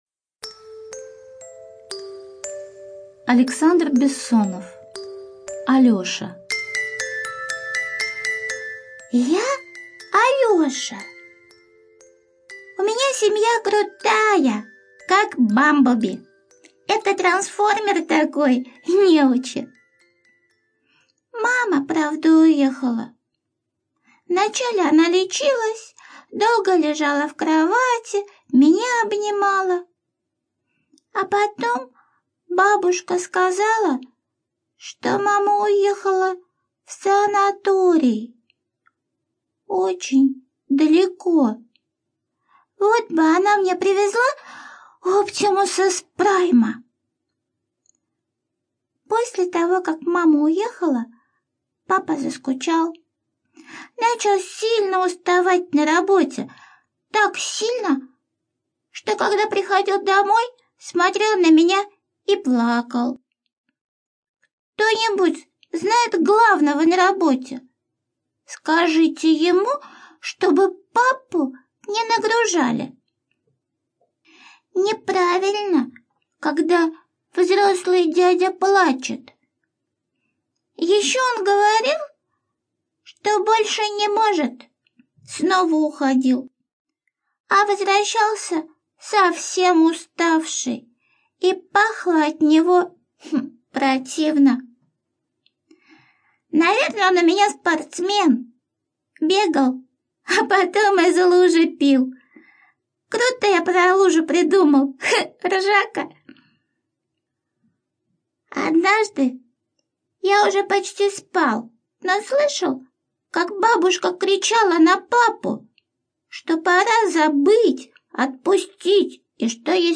Студия звукозаписиНижневартовская центральная городская библиотека